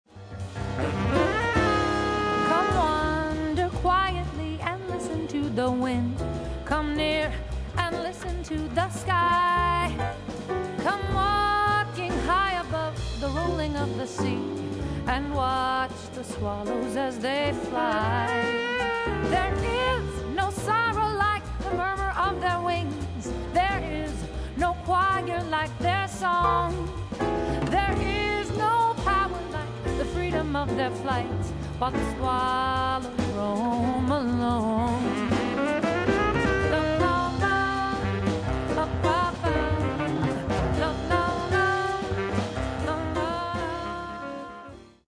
vocals
piano
saxes
bass
drums
guitar
and straight-ahead jazz still create musical magic.